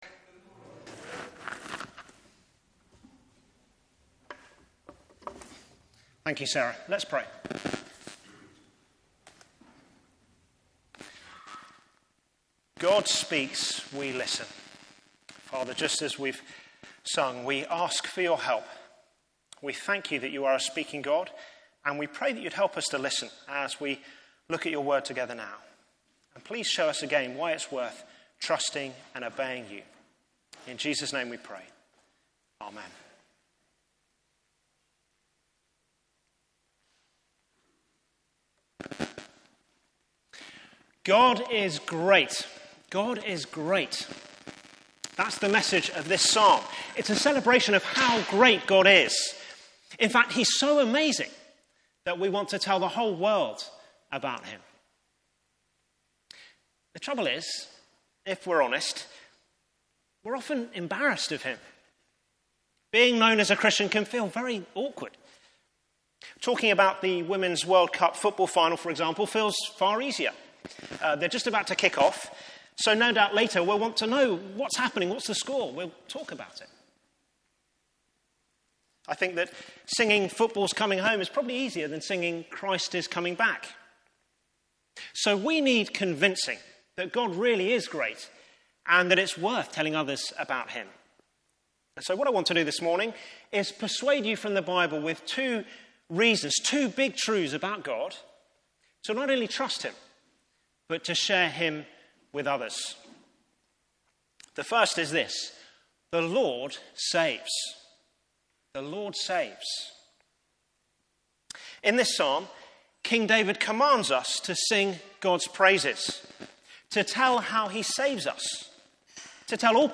Media for Morning Service on Sun 20th Aug 2023 10:30 Speaker
Theme: Sermon In the search box please enter the sermon you are looking for.